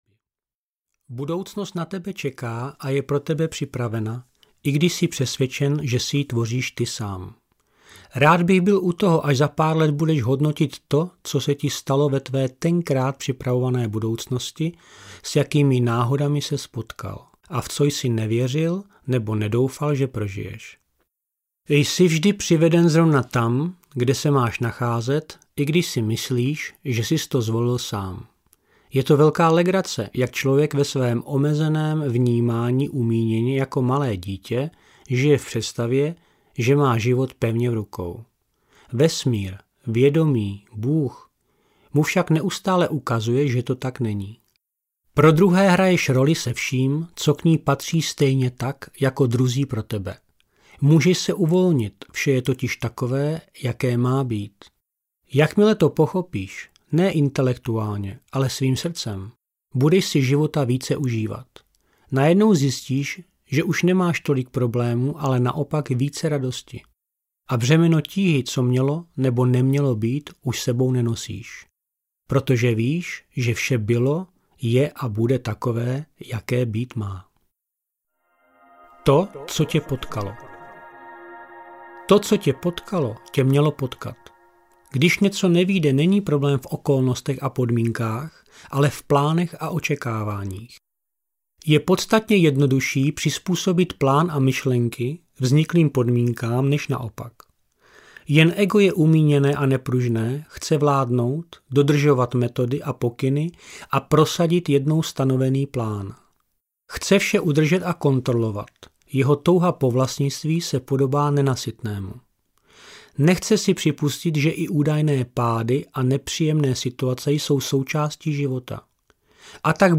Sedíš ve správném vlaku audiokniha
Ukázka z knihy